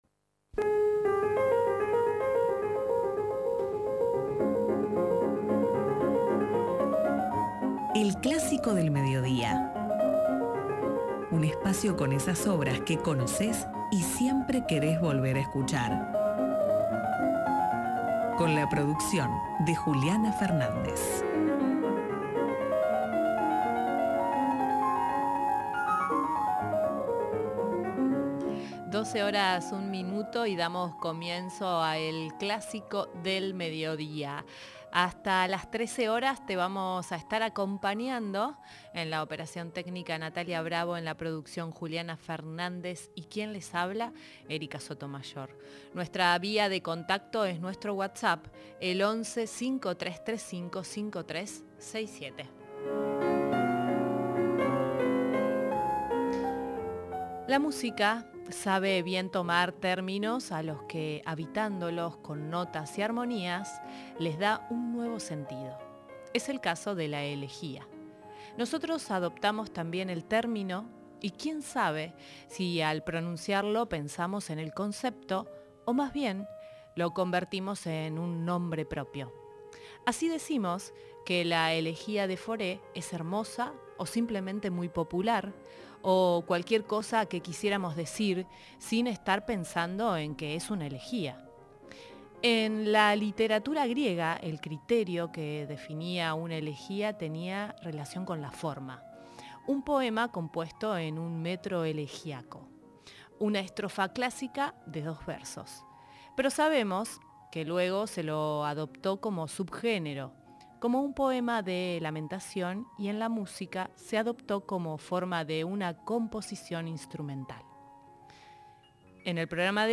La música adoptó la elegía como forma de una composición instrumental. En este programa vamos a compartir varias y de diferentes períodos con obras de Fauré, Purcell o Grieg, entre otros compositores.